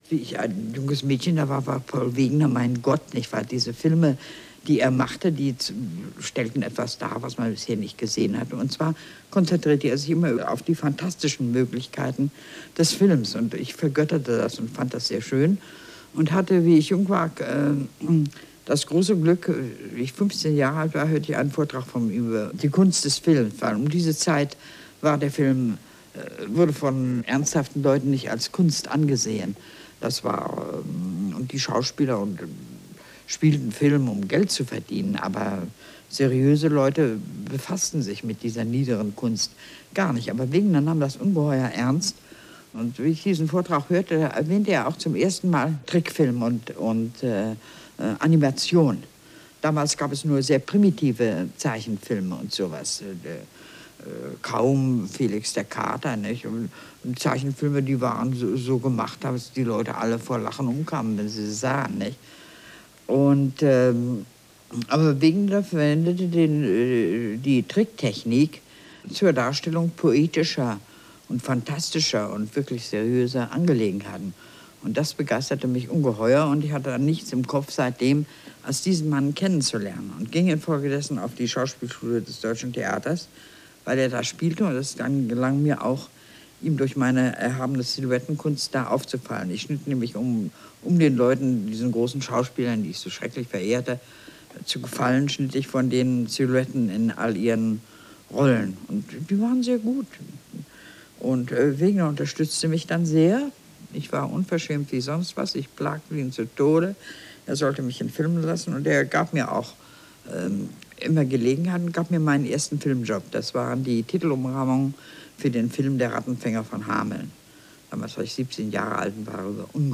O-Ton Lotte Reiniger